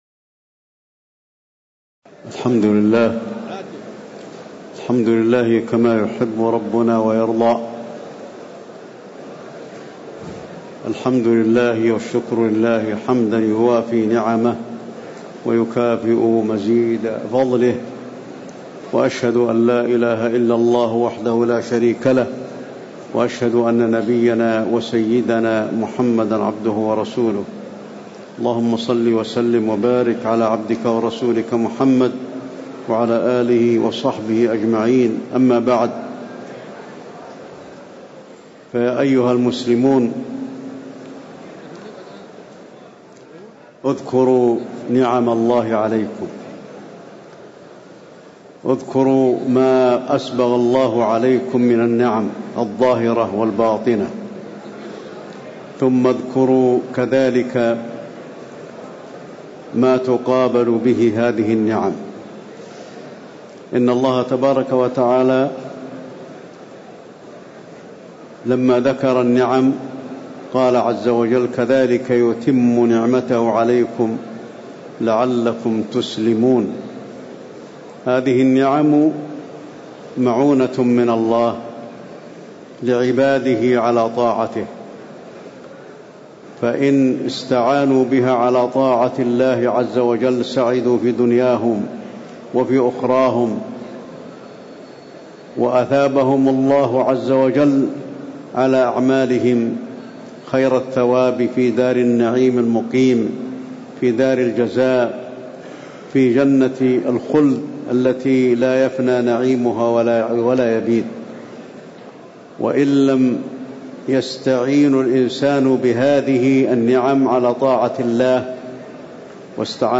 خطبة الخسوف المدينة- الشيخ علي الحذيفي
تاريخ النشر ١٥ ذو القعدة ١٤٣٨ هـ المكان: المسجد النبوي الشيخ: فضيلة الشيخ د. علي بن عبدالرحمن الحذيفي فضيلة الشيخ د. علي بن عبدالرحمن الحذيفي خطبة الخسوف المدينة- الشيخ علي الحذيفي